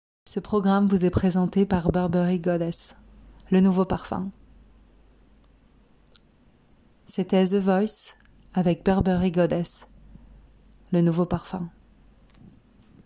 25 - 61 ans - Contralto